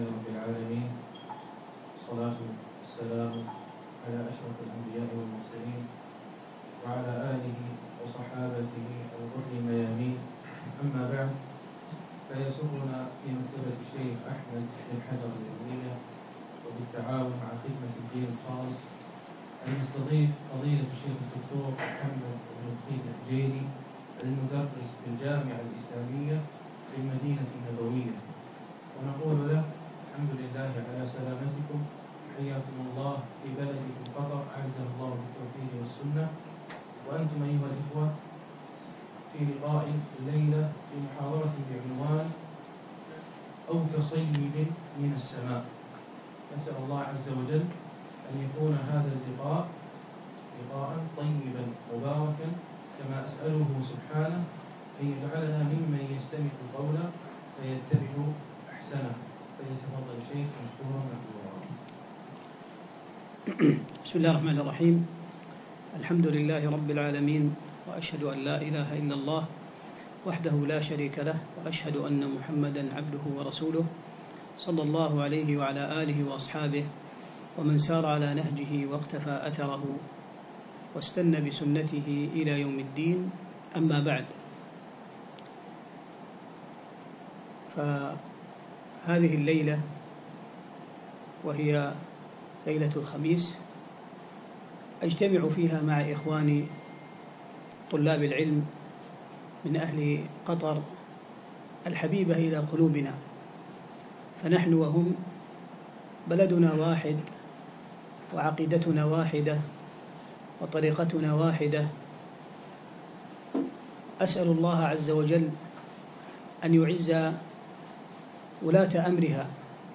من محاضرات الشيخ في دولة قطر
محاضرة أو كصيب من السماء - قطر